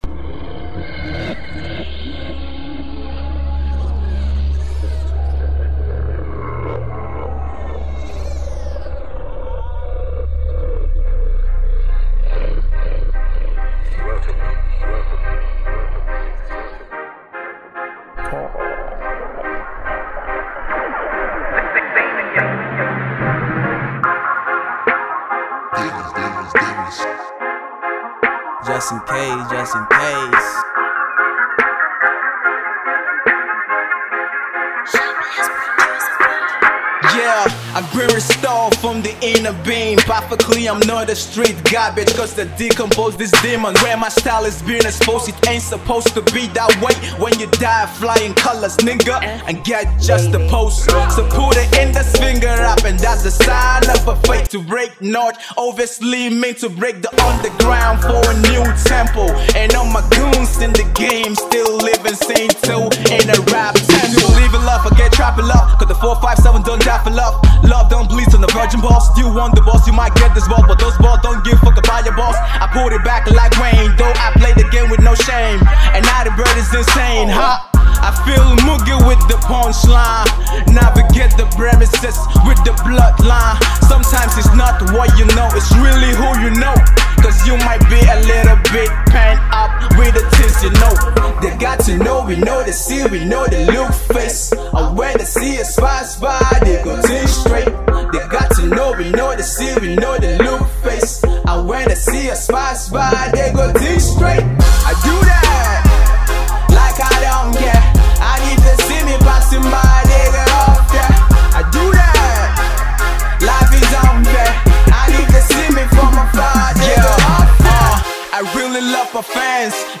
Alternative Rap
Up and coming rapper
hip-hop